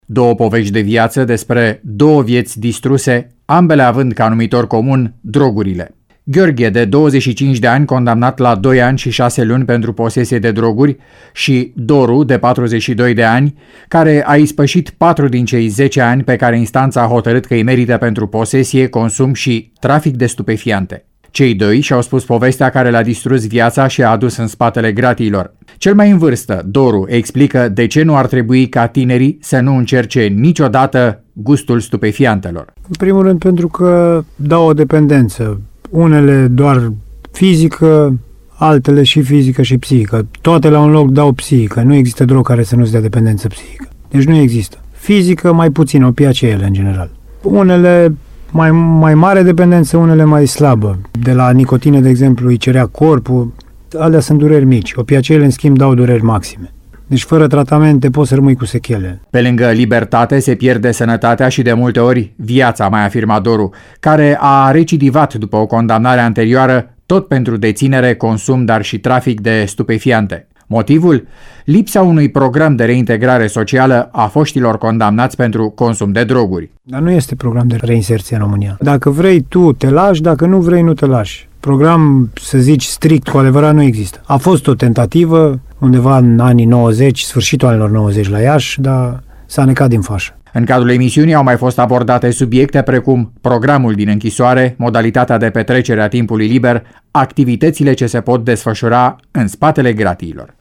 Emisiunea „Ascută cum te ascult” a fost realizată din spatele gratiilor, la Penitenciarul din Timişoara.
din studioul de radio cu circuit închis, Popa Şapcă 7.